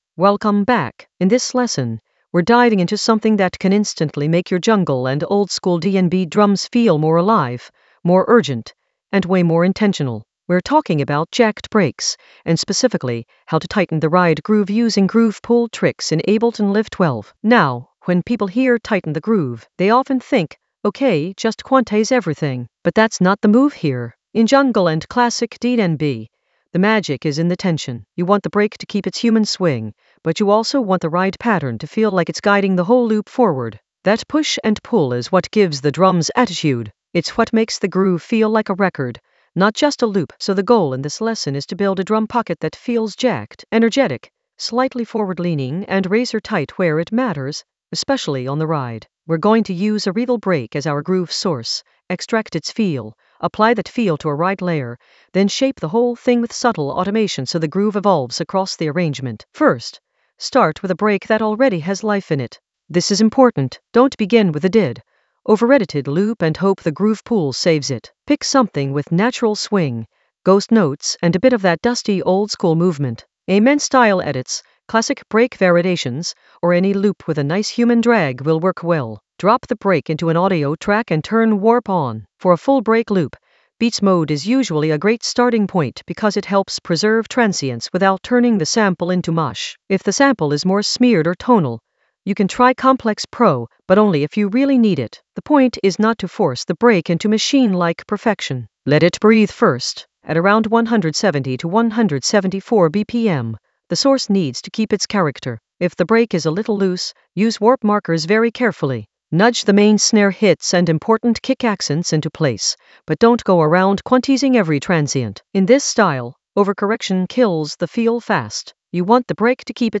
An AI-generated advanced Ableton lesson focused on Jacked Breaks: ride groove tighten using groove pool tricks in Ableton Live 12 for jungle oldskool DnB vibes in the Automation area of drum and bass production.
Narrated lesson audio
The voice track includes the tutorial plus extra teacher commentary.